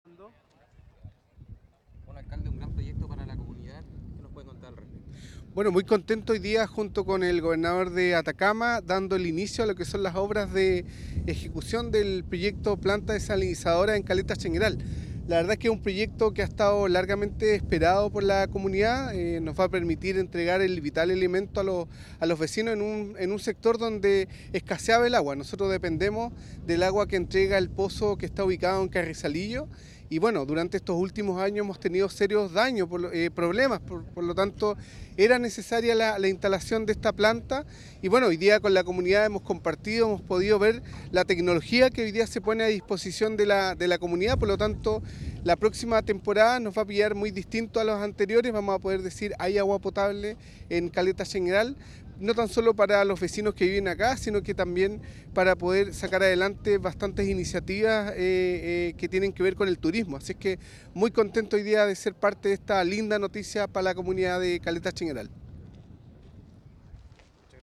En una ceremonia encabezada por el Gobernador de Atacama, Miguel Vargas Correa, y el alcalde de Freirina, César Orellana, se firmó el contrato para la instalación de una planta desalinizadora en Caleta Chañaral de Aceituno.
Alcalde-Cesar-Orellana-.mp3